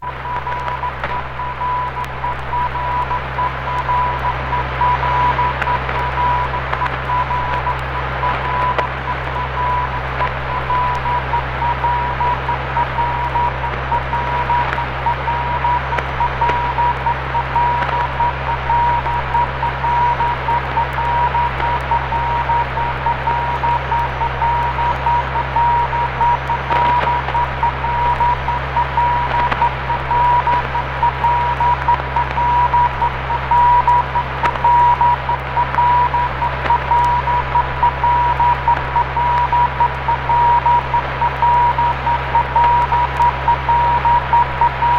58年に打ち上げられた米ソの13機からの音。宇宙へ飛び立った犬、ライカの心音も。多くのフィールドレコーディング同様に、刺激溢れる音の世界です。
Field Recording, Non Music　USA　12inchレコード　33rpm　Mono